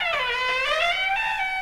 دانلود آهنگ در 9 از افکت صوتی اشیاء
دانلود صدای در 9 از ساعد نیوز با لینک مستقیم و کیفیت بالا
جلوه های صوتی